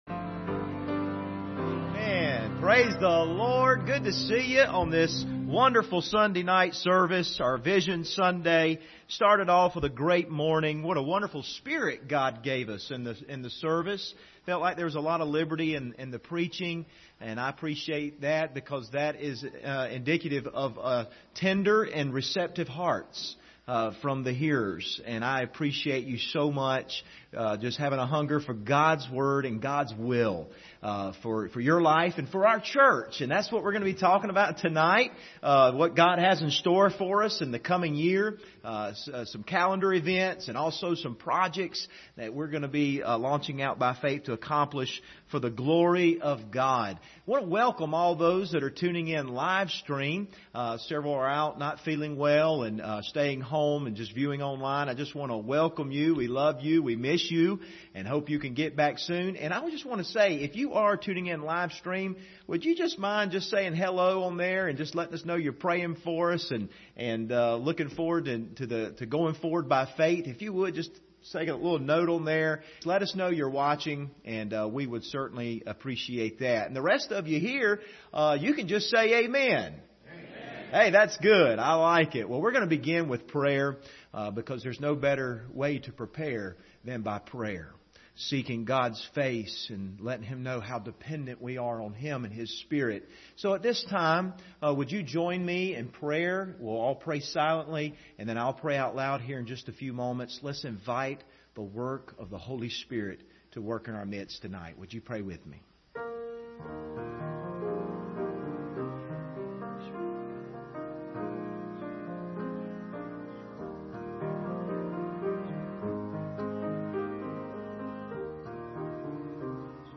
Vision Night – Full Service – Crooked Creek Baptist Church